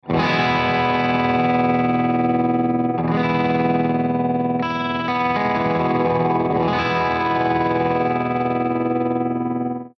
034_BUCKINGHAM_TREMOLO4_P90